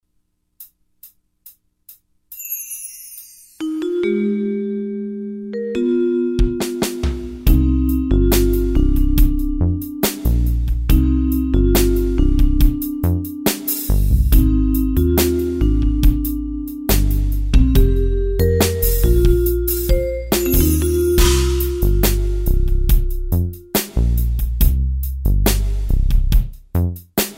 Key of C minor
Backing track only.